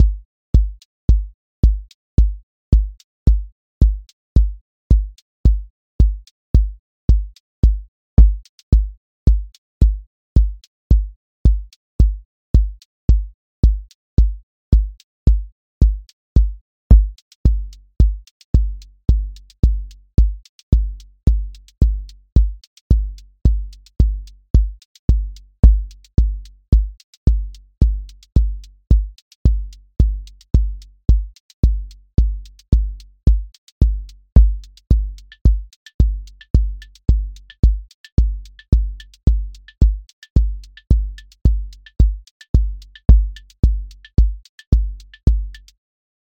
QA Listening Test house Template: four_on_floor
Build a 3-against-4 polyrhythmic piece using additive synthesis voices — detuned sine clusters that create warmth through beating frequencies. Three voices: a kick pattern in 4, a melodic voice in 3, and a hat/percussion voice that alternates between both subdivisions. Each voice built from stacked sines (2-4 partials with slight detuning). No subtractive filtering — all tone shaping through additive construction.